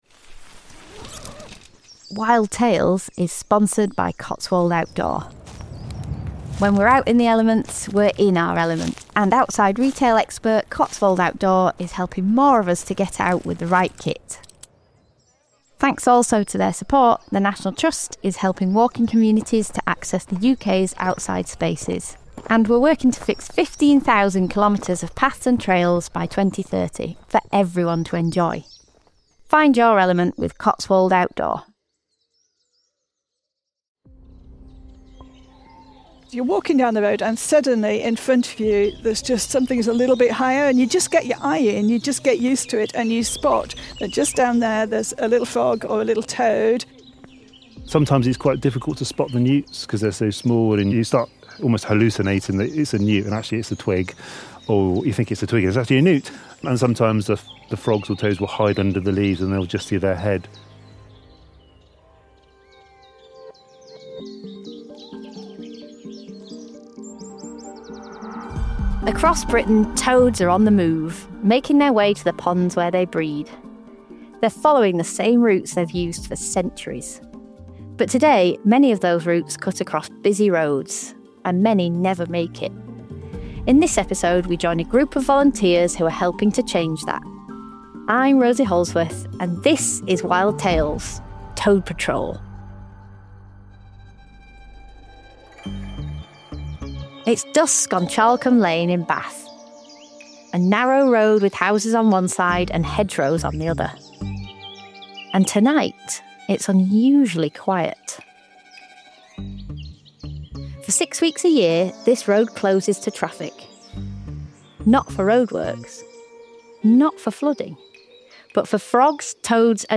In this episode of Wild Tales, we spend the night with the Charlcombe Lane Toad Patrol, discovering the dedication and determination it takes to give these remarkable animals a fighting chance.